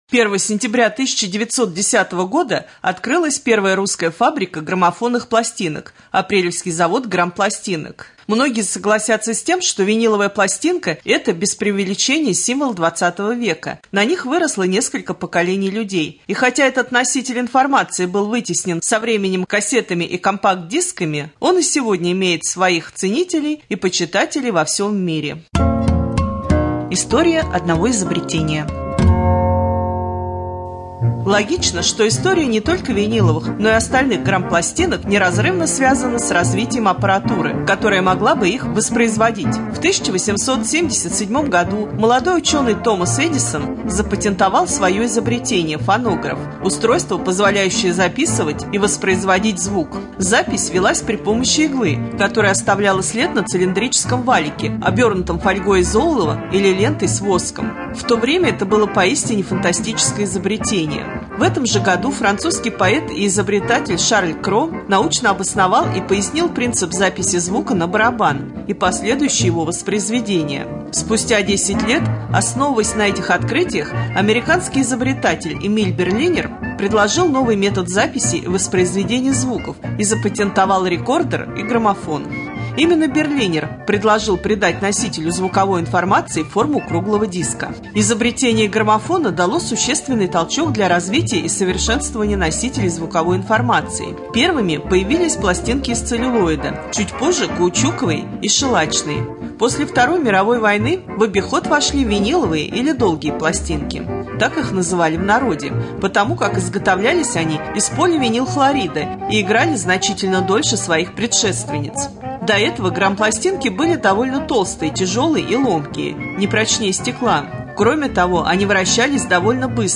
01.09.2017г. в эфире Раменского радио